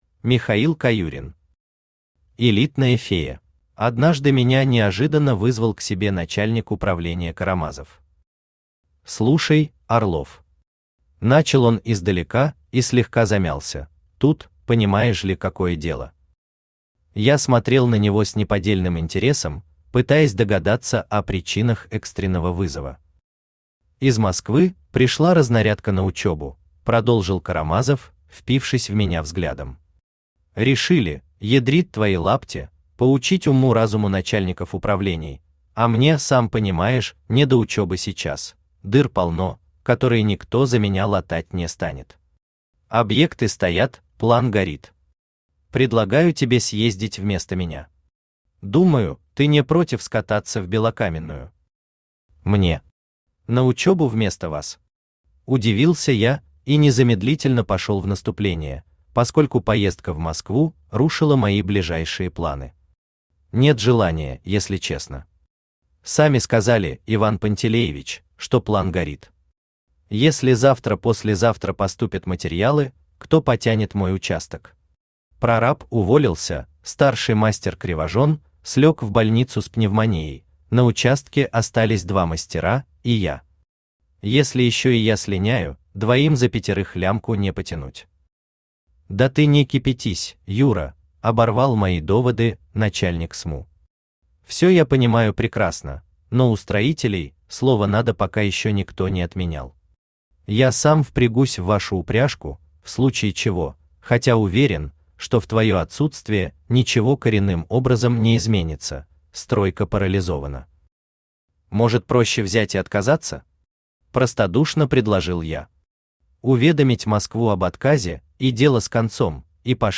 Аудиокнига Элитная фея | Библиотека аудиокниг
Aудиокнига Элитная фея Автор Михаил Александрович Каюрин Читает аудиокнигу Авточтец ЛитРес.